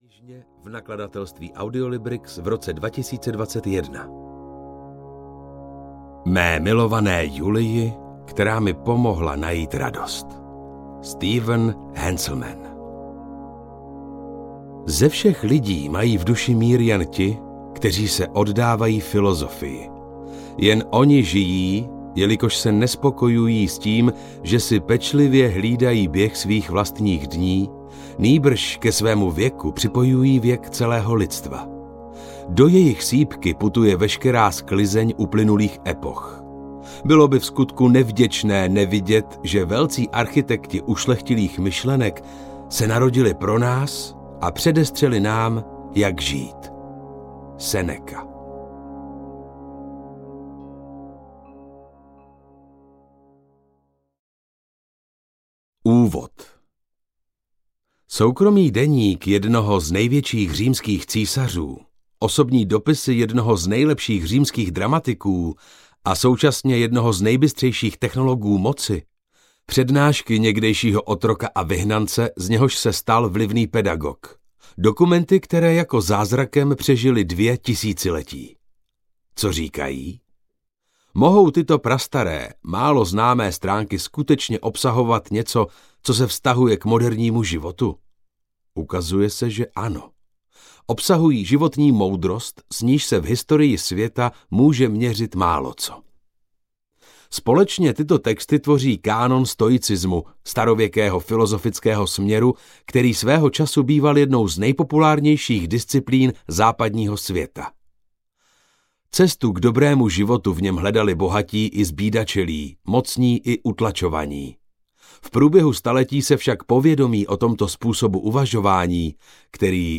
Audio knihaStoikův průvodce pro každý den
Ukázka z knihy